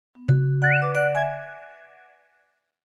Cheerful_whistle.ogg